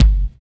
118 KICK.wav